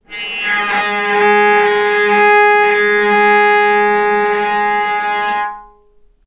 healing-soundscapes/Sound Banks/HSS_OP_Pack/Strings/cello/sul-ponticello/vc_sp-G#3-ff.AIF at a9e67f78423e021ad120367b292ef116f2e4de49
vc_sp-G#3-ff.AIF